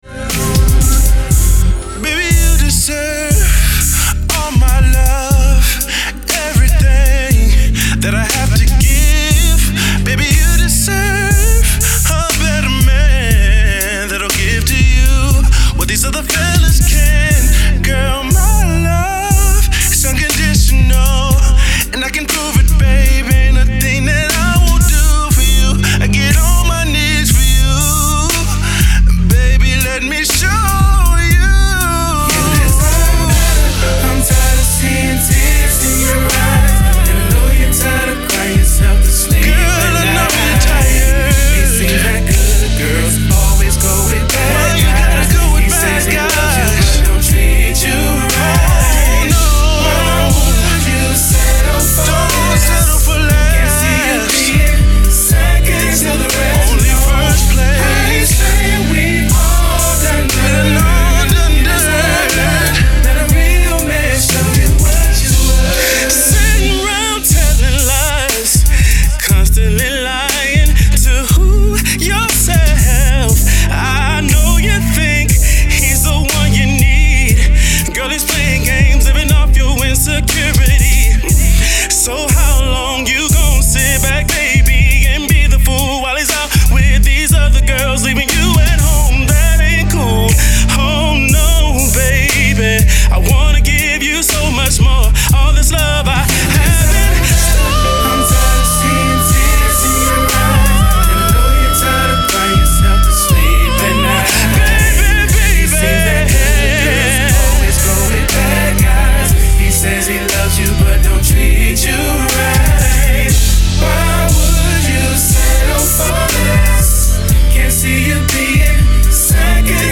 RnB
4 man r&b singing group